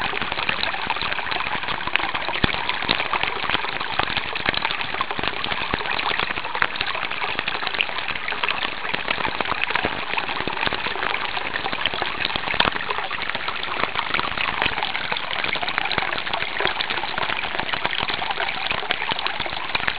hosmer brook
hosmer_brook.wav